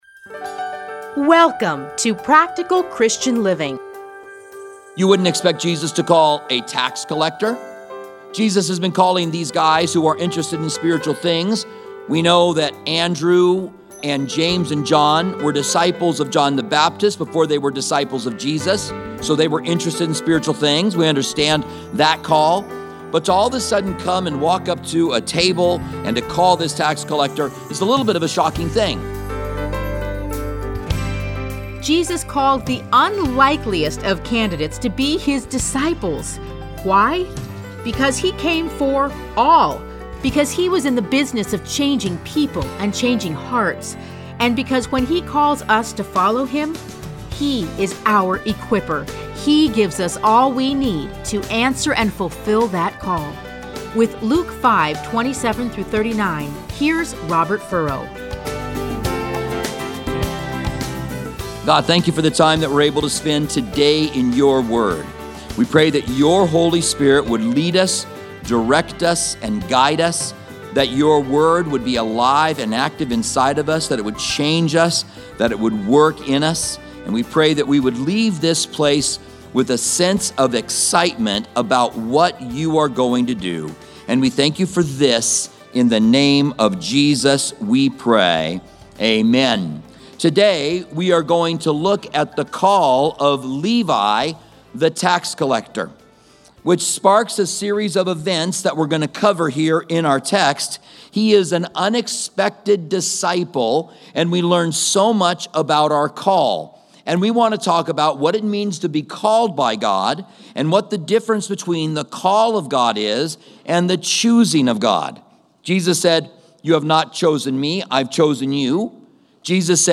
Listen to a teaching from Luke 5:27-39.